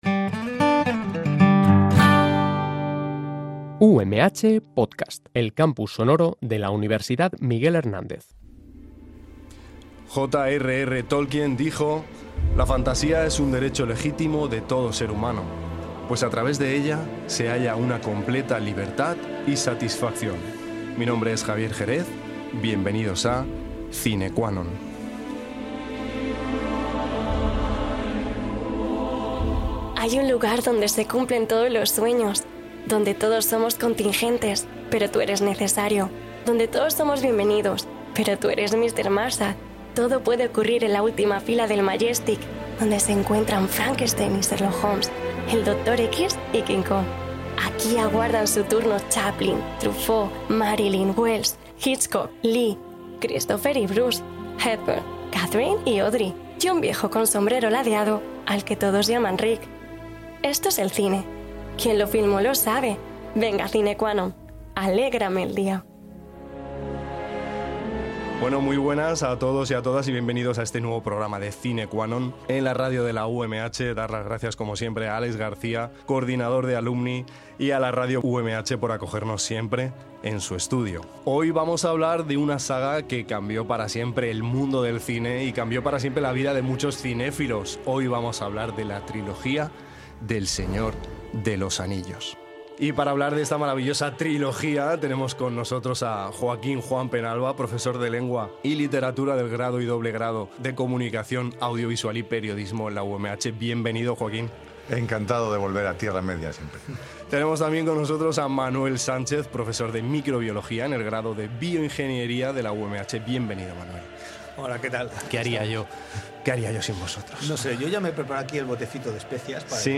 Cine Qua Non traerá invitados de todo tipo, incluyendo miembros del alumnado UMH, para hablar sobre cine, series, videojuegos y disciplinas impartidas en nuestra maravillosa universidad.